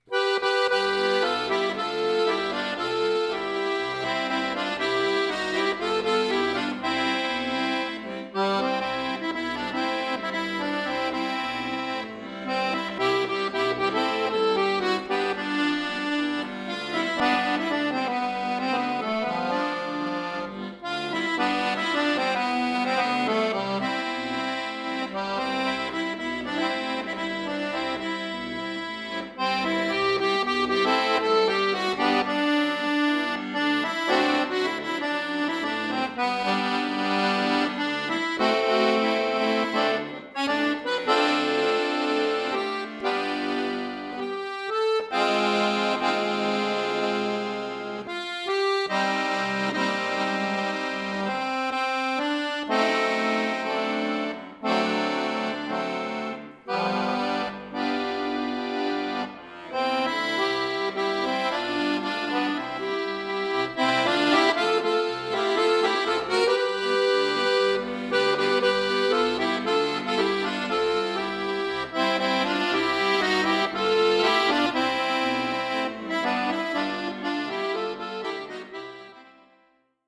Folksong , Mexikanisch